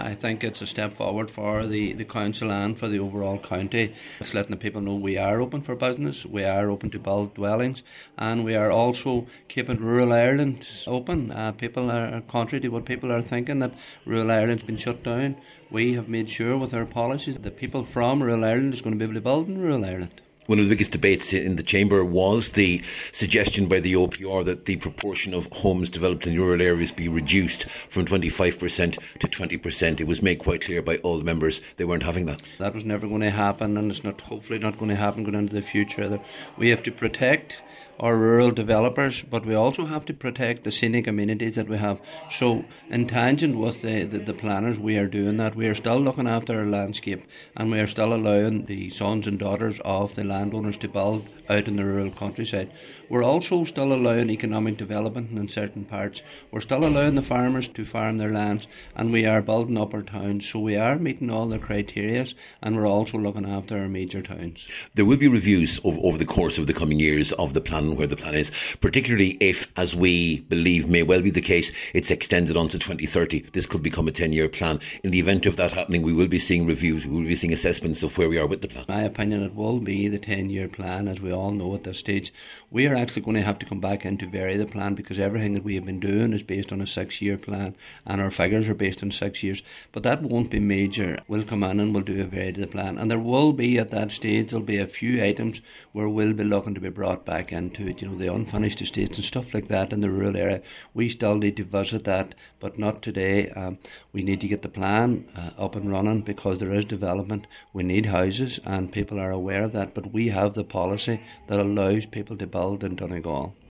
Councillor Paul Canning says the plan shows that Donegal is open for business.